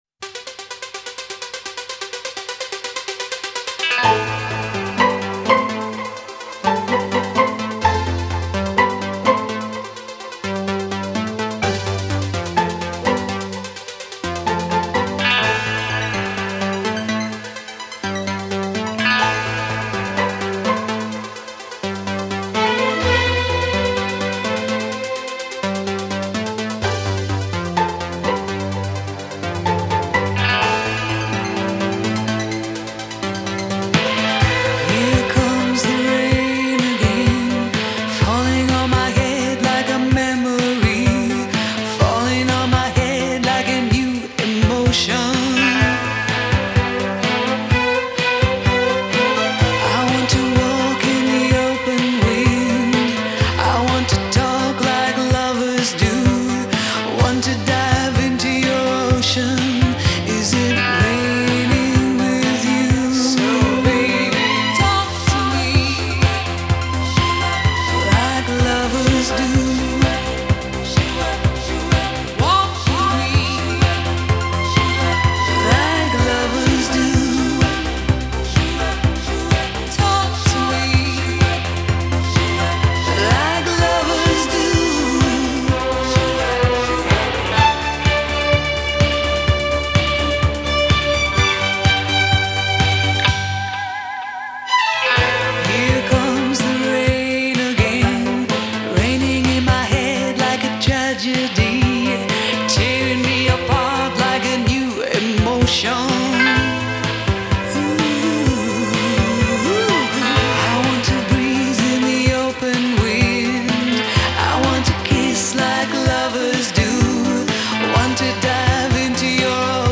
синти-поп-дуэт